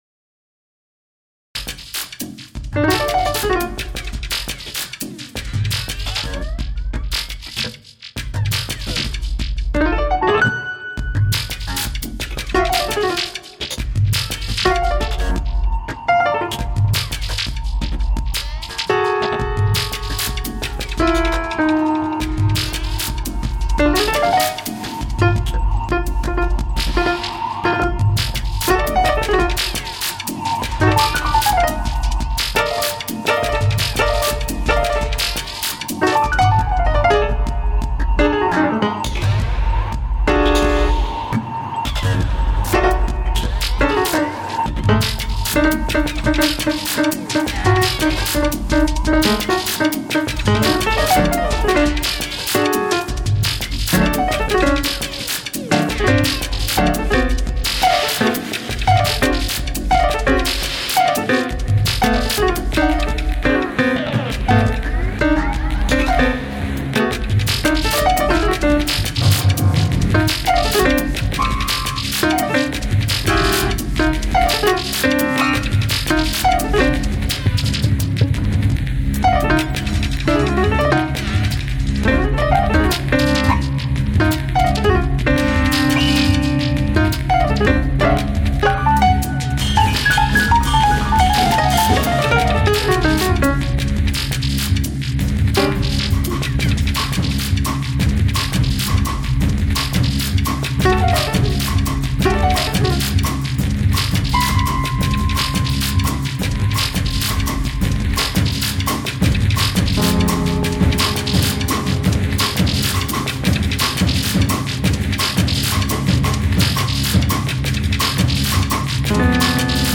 Nouveau projet orienté jazz électronique
quintet